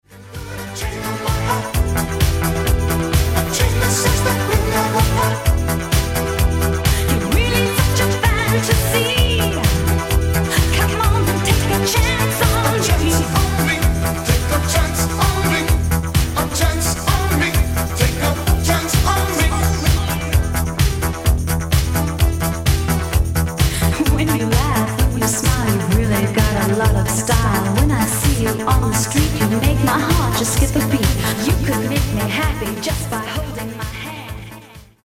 Genere: Disco | High Energy